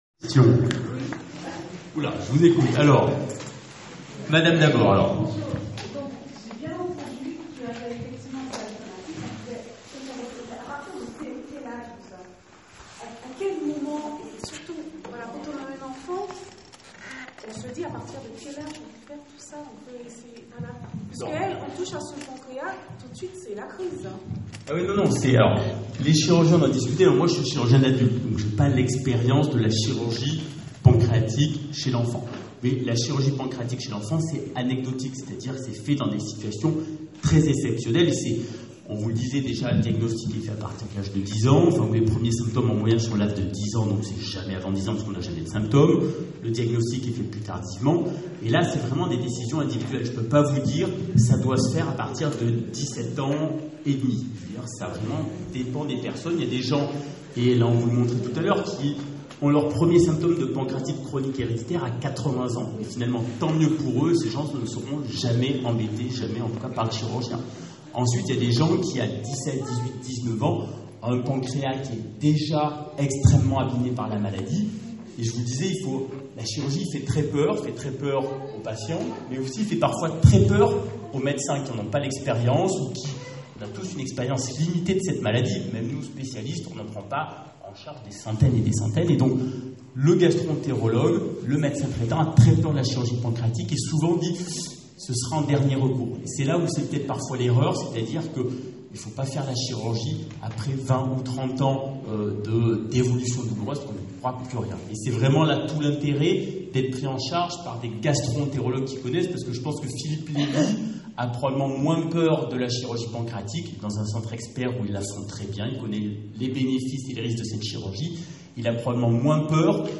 Rencontres Médecins-Malades de l'association APCH le 9 juin 2018 à ST GERMAIN SUR MOINE La 6 ème RENCONTRE sur La Pancréatite Chronique Héréditaire A NOTER : Nous vous mettons à disposition des enregistrements sonores effectués durant la réunion pour que ceux, qui n'ont pas pu se déplacer, puissent suivre quelque peu ce qui a été dit.